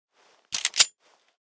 wpn_pistol10mm_jam.wav